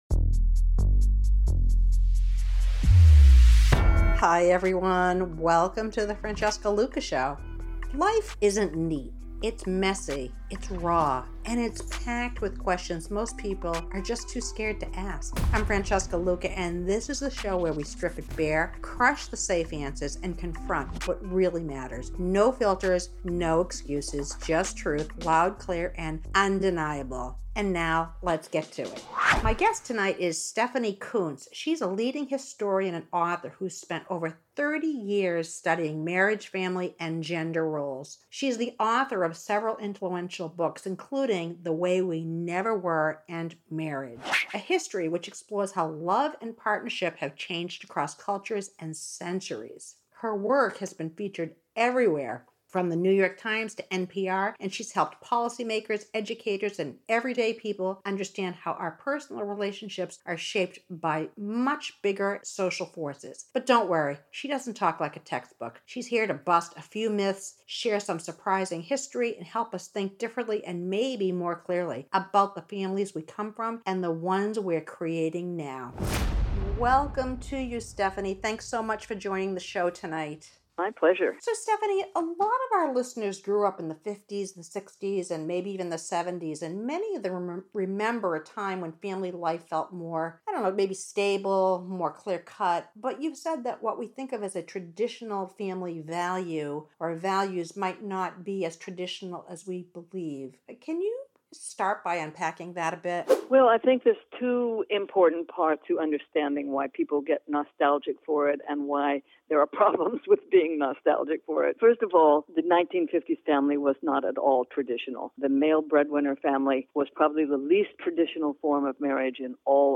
The show is feisty and fearless without being abrasive. We discuss hot topics in a provocative way with a say it like you mean it and no nonsense attitude.